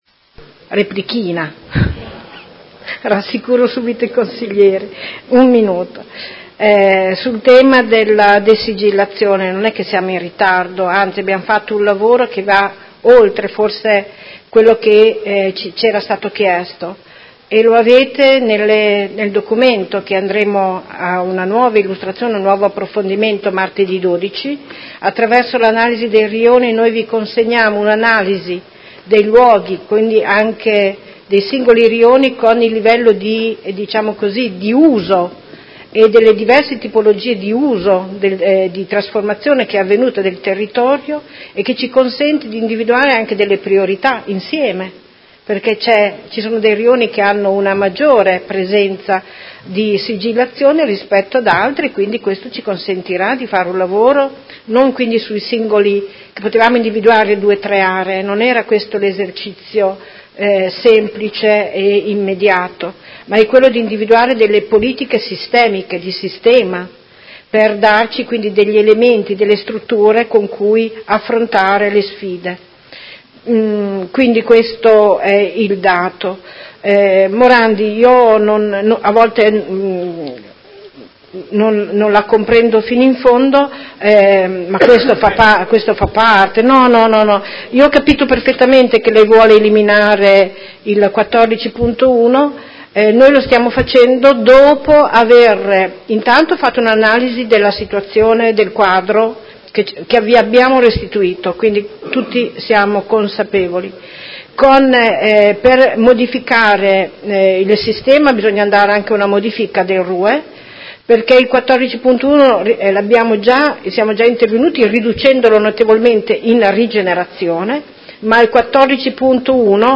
Seduta del 07/02/2019 Delibera. Modifiche al Regolamento contenente i criteri e le modalita' applicative dell'art. 14..1 del RUE, approvato con propria deliberazione n. 38 del 16.06.2008 e successive modificazioni